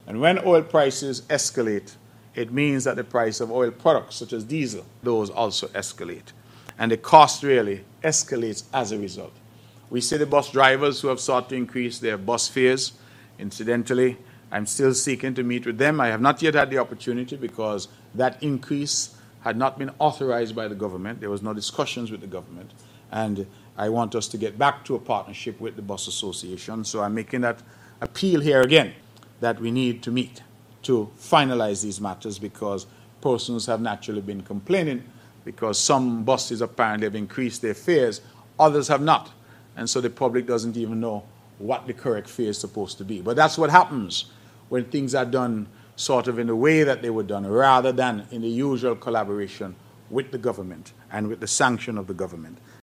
Here is Mr. Brantley.
Premier Mark Brantley.